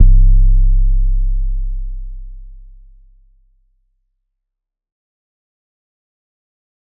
808 (Cash Out).wav